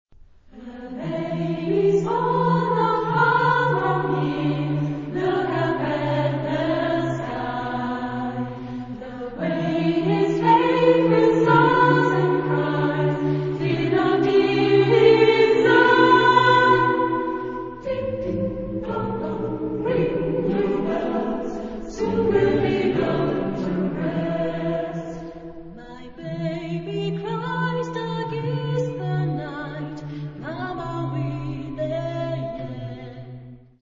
Chorgattung: SATB  (4 gemischter Chor Stimmen )
Tonart(en): e-moll